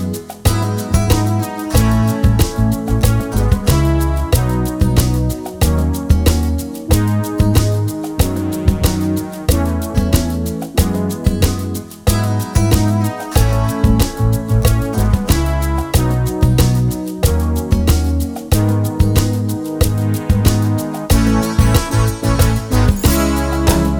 no Backing Vocals Soft Rock 4:37 Buy £1.50